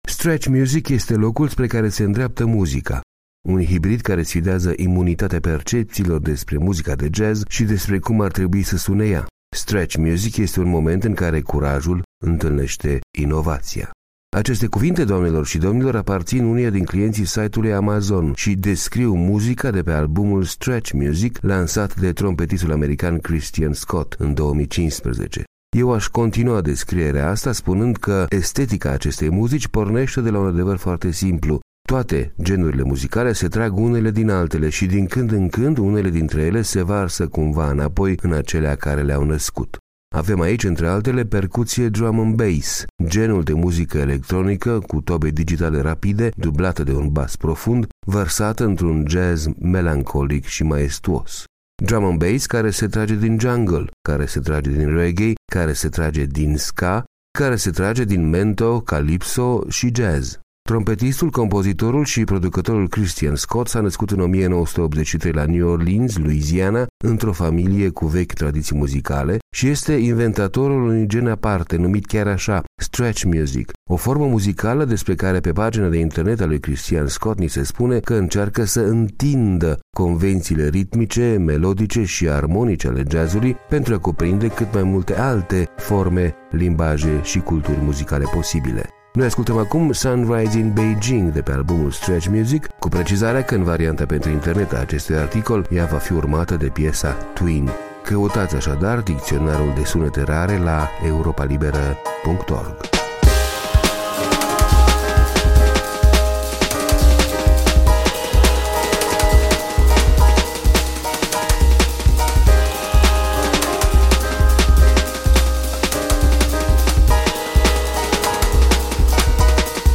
O formă muzicală care încearcă să „întindă” convențiile ritmice, melodice și armonice ale jazz-ului.